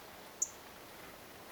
keltasirkun ääni
keltasirkkulinnun_tik.mp3